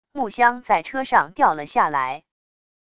木箱在车上掉了下来wav音频